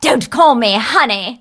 bea_ulti_vo_02.ogg